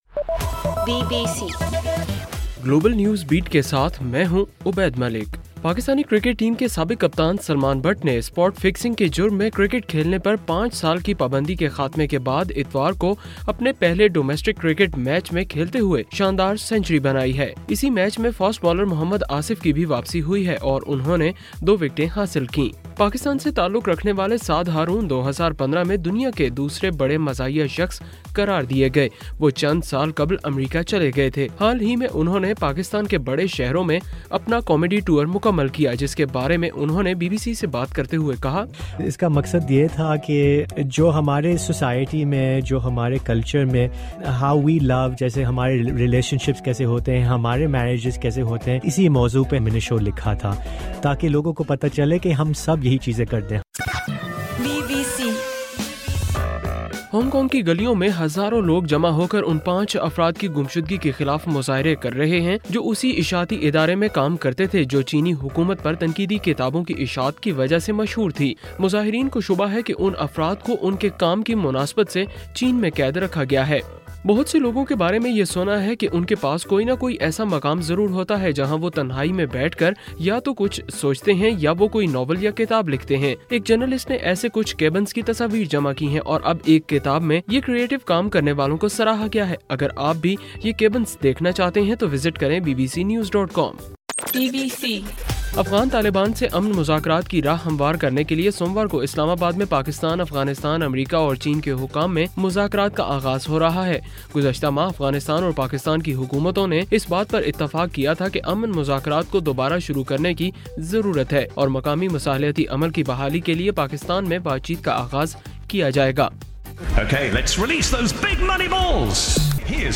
جنوری 10: رات 12 بجے کا گلوبل نیوز بیٹ بُلیٹن